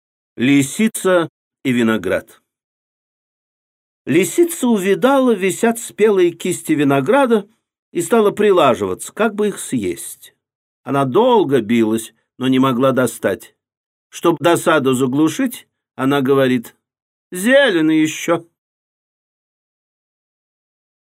Лисица и виноград - аудио басня Толстого - слушать онлайн